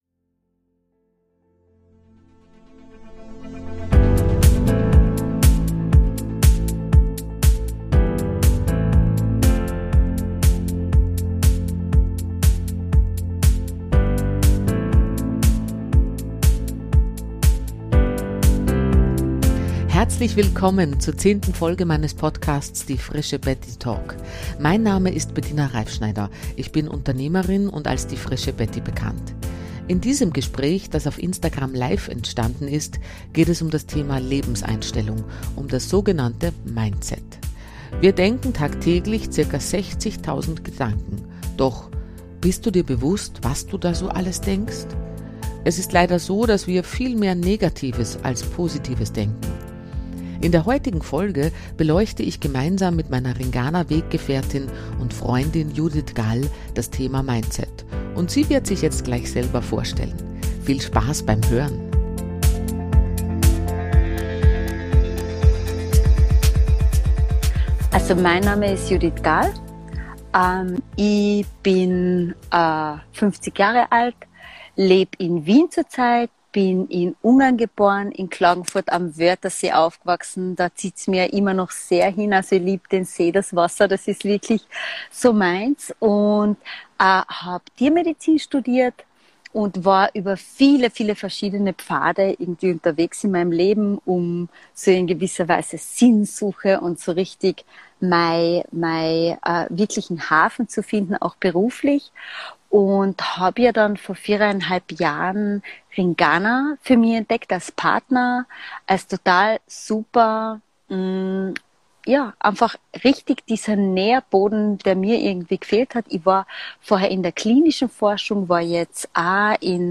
In diesem Gespräch, das auf Instagram live entstanden ist, geht es um das Thema Lebenseinstellung um das sogenante Mindset: Wir denken tagtäglich ca 60000 Gedanken, doch - bist du dir bewusst was du da so alles denkst? Es ist leider so, dass wir viel mehr Negatives als Positives denken.